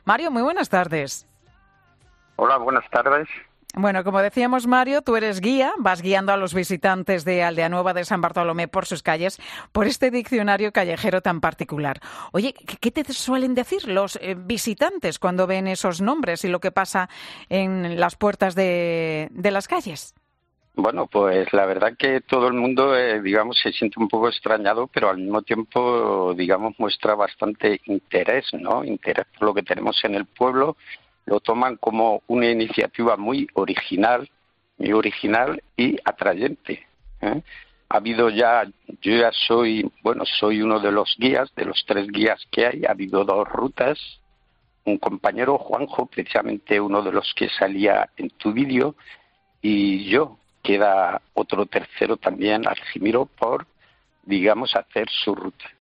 Escucha las palabras de un vecino de un pueblo toledano sobre la reacción de los turistas que acuden allí